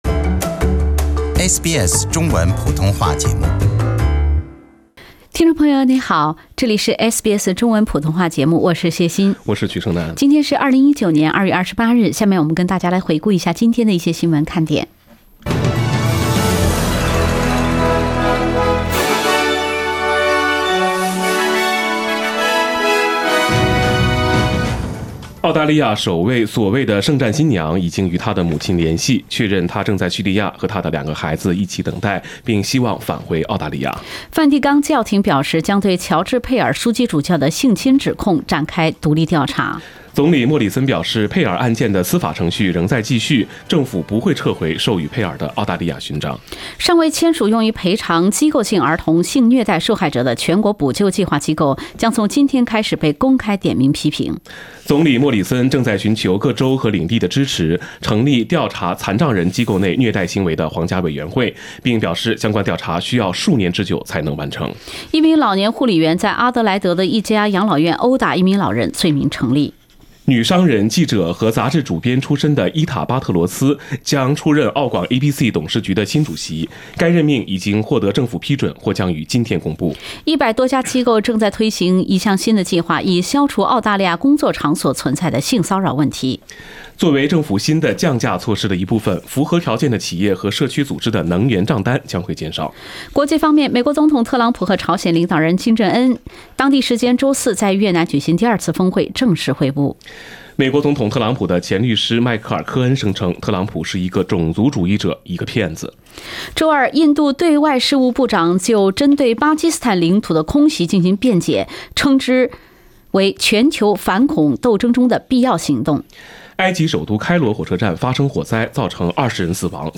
SBS早新闻 （2月28日）